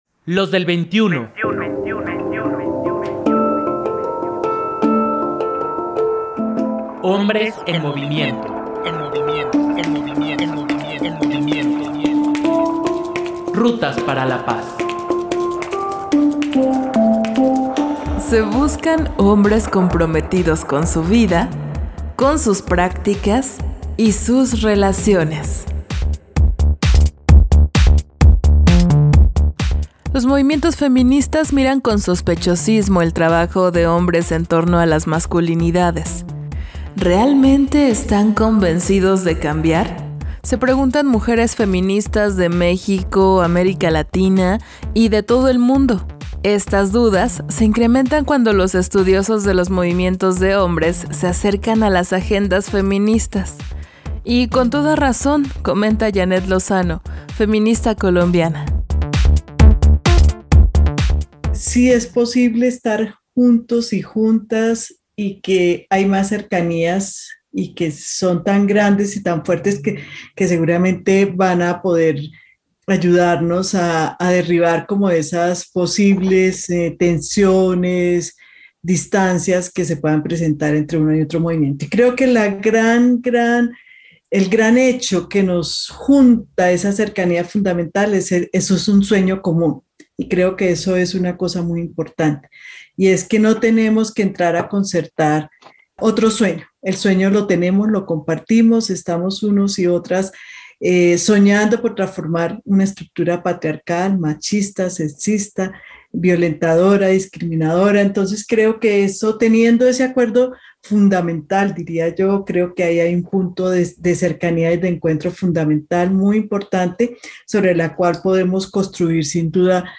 Este diagnóstico es un documento histórico, coincidieron las y los participantes en este diálogo, realizado desde México, Colombia y Costa Rica.